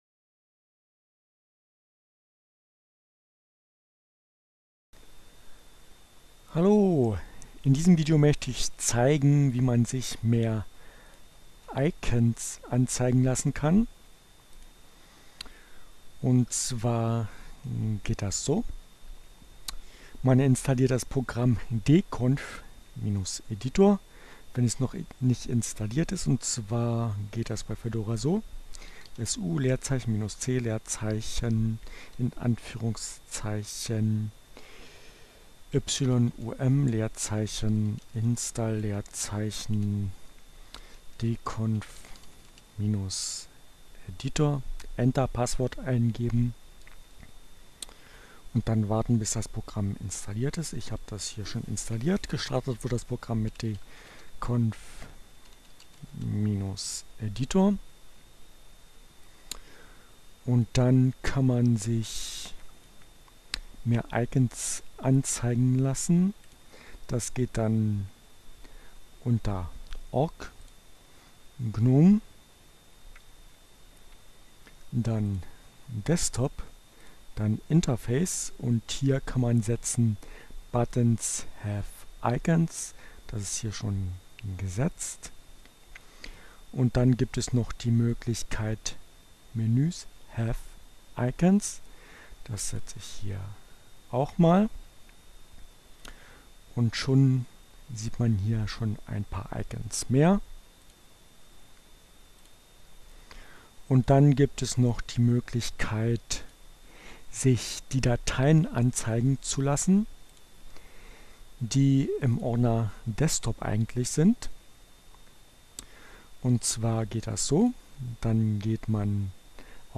Tags: CC by-sa, Fedora, Gnome, Linux, Neueinsteiger, Ogg Theora, ohne Musik, screencast, gnome3, dconf-editor